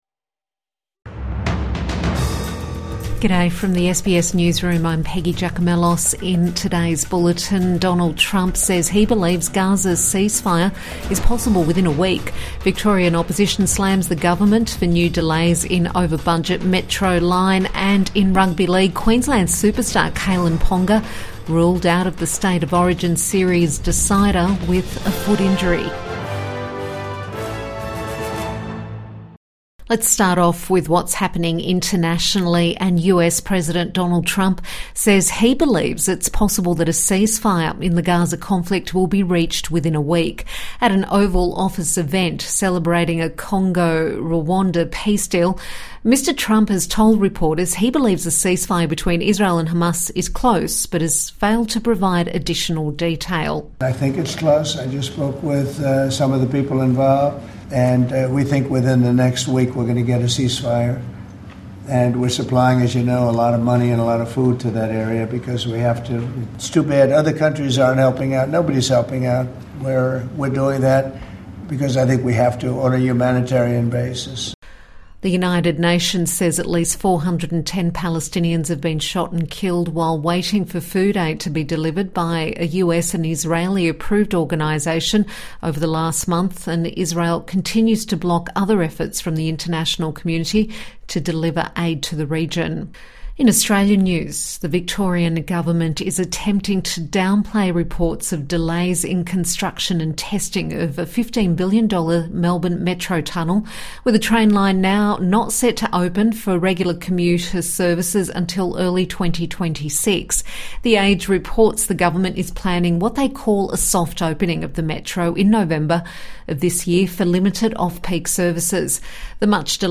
Evening News Bulletin 28 June 2025